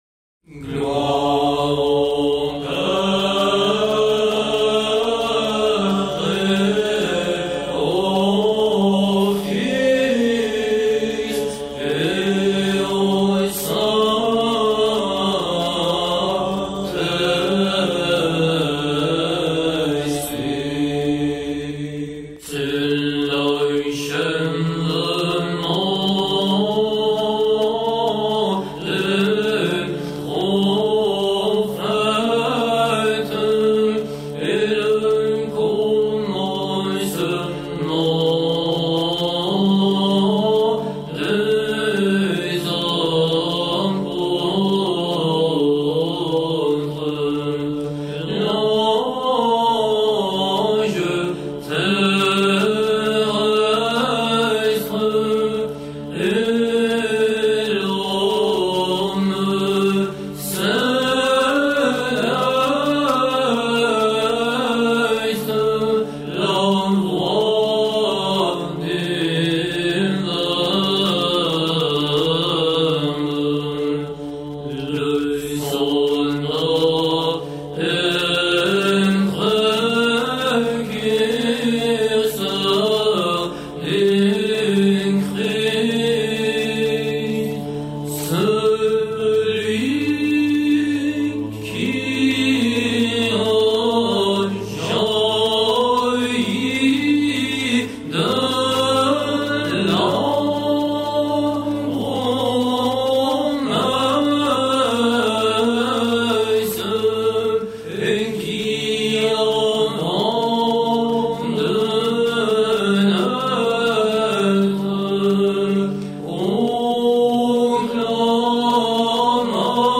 L’ison est le seul accompagnement de la musique byzantine.
Musique byzantine en français
Chœur du Monastère la Théotokos et st Martin.